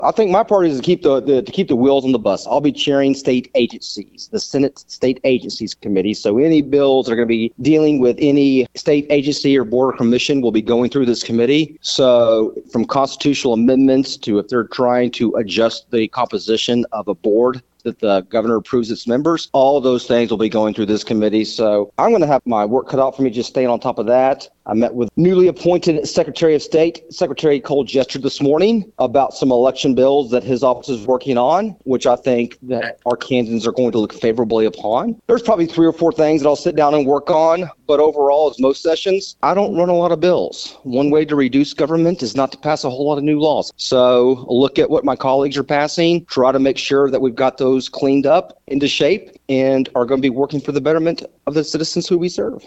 With the start of the 95th legislative session in Arkansas, Scott Flippo, State Senator representing District 23, spoke with KTLO, Classic Hits and The Boot News to share his experiences of the past few days.